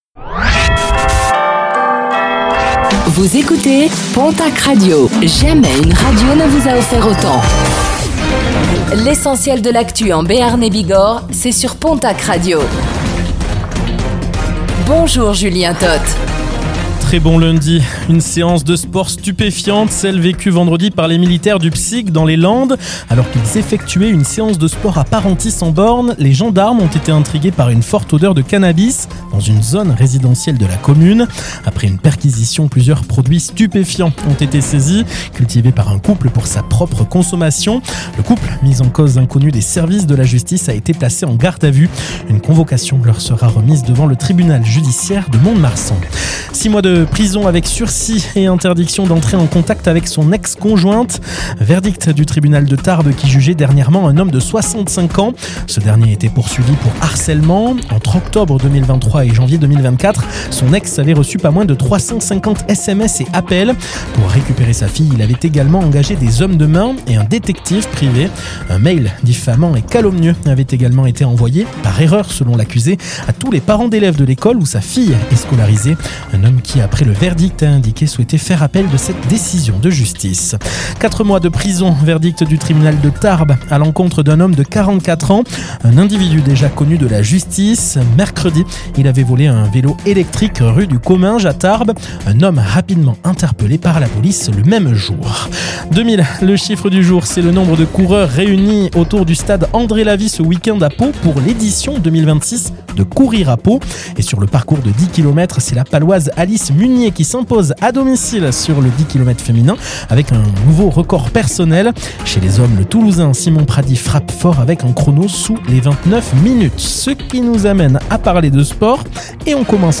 Réécoutez le flash d'information locale de ce lundi 02 février 2026